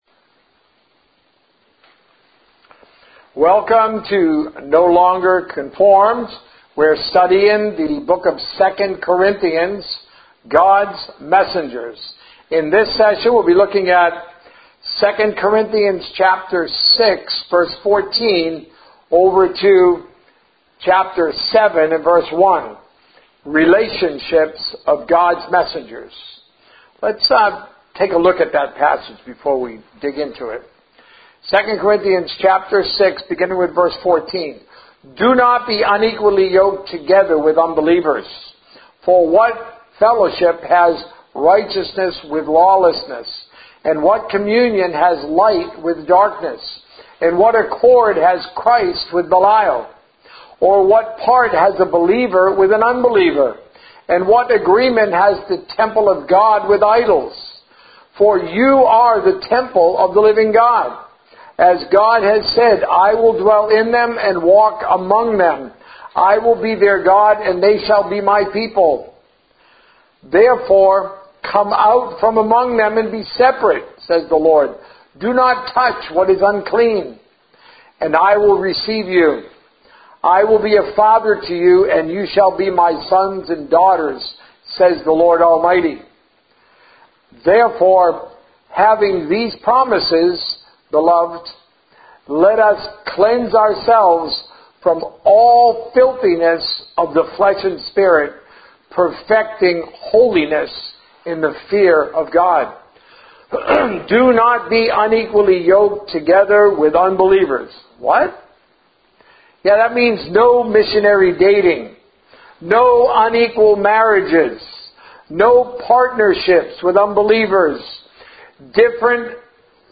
Sermons - No Longer Conformed